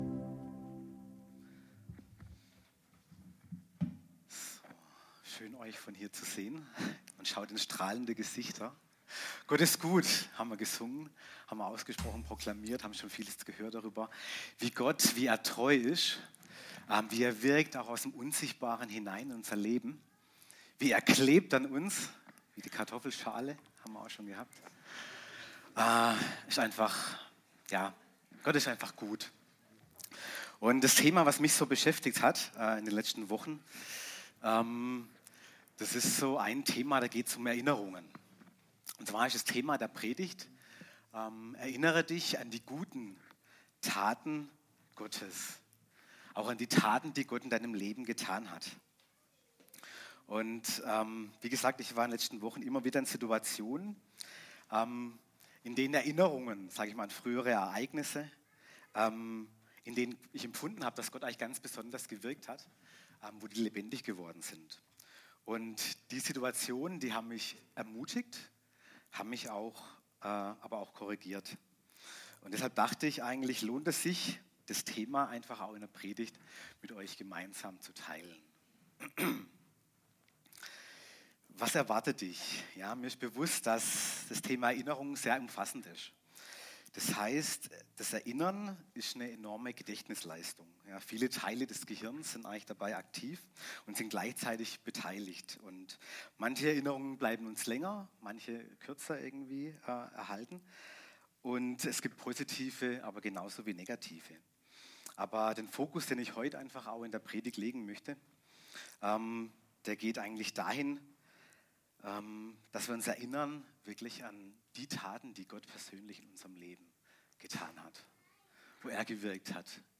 Kategorie Predigten